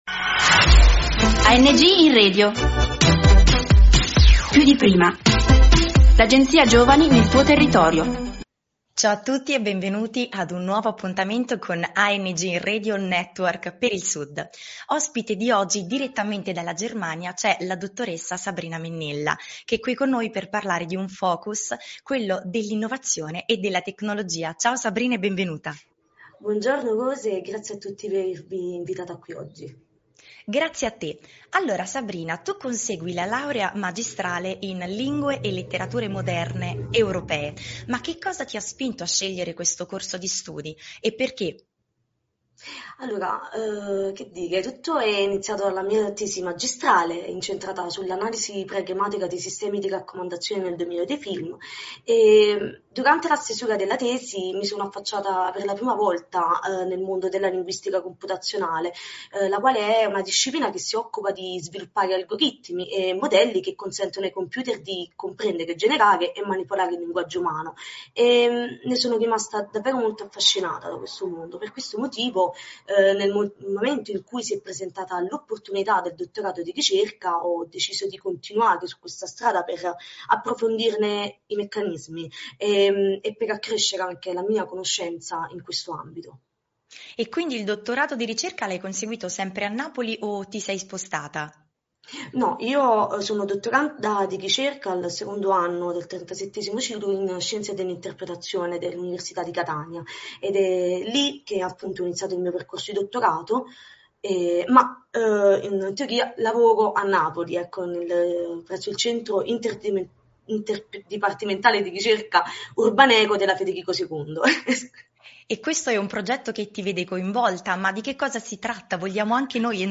Nell’ambito della progettazione ANG Radio Network del sud, attivate una serie di interviste al fine di soddisfare le richieste e le esigenze della cittadinanza.